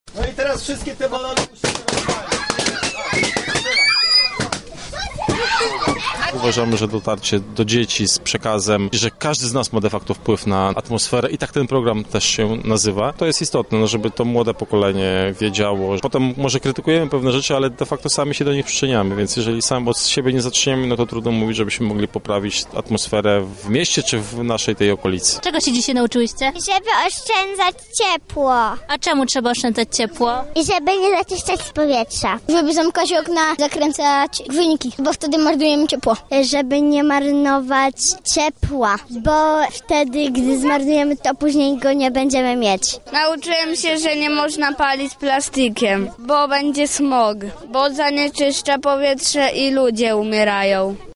Zastępca prezydenta Artur Szymczyk tłumaczył między innymi, jak ważna jest edukacja najmłodszych w kwestii dbania o środowisko: